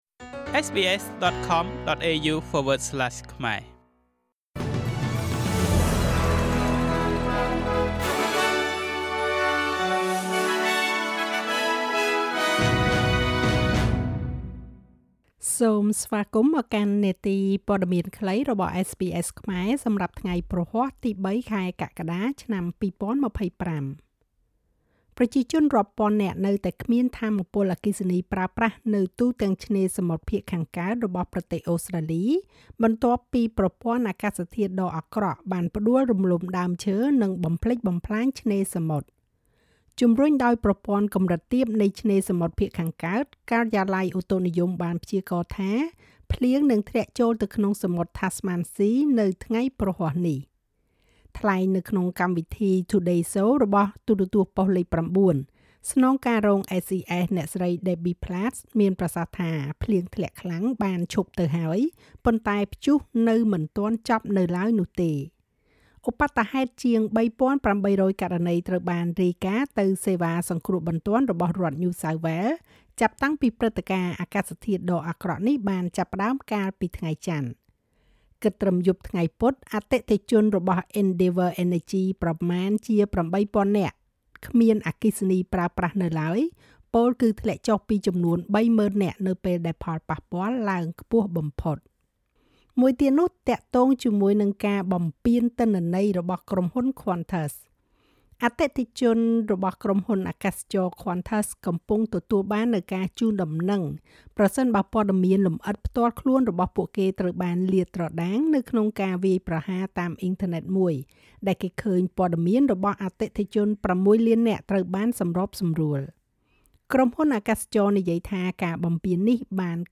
នាទីព័ត៌មានខ្លីរបស់SBSខ្មែរ សម្រាប់ថ្ងៃព្រហស្បតិ៍ ទី៣ ខែកក្កដា ឆ្នាំ២០២៥